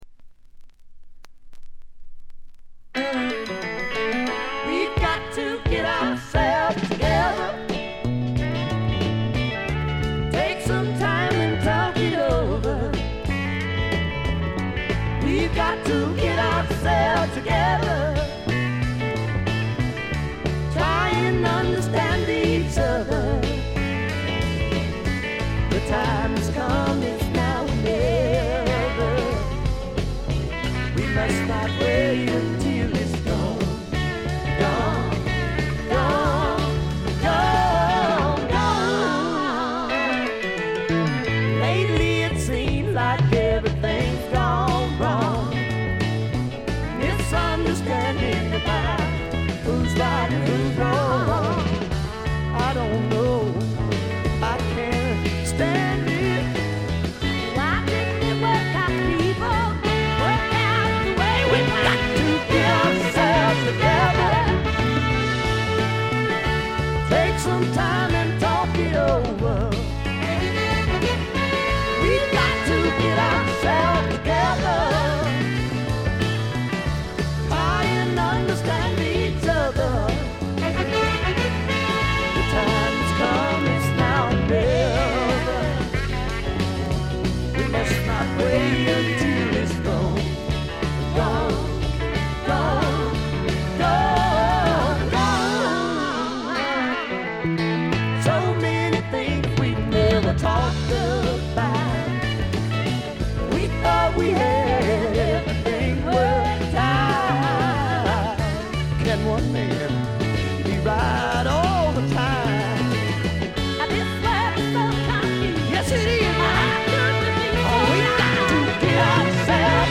ところどころで軽微なチリプチ。
まさしくスワンプロックの原点ともいうべき基本中の基本盤。
試聴曲は現品からの取り込み音源です。